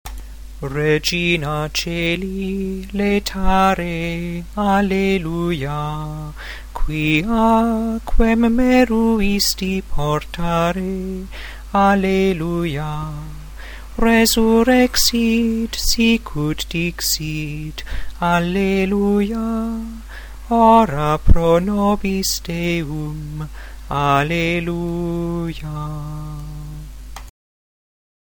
2. Gregorian Chant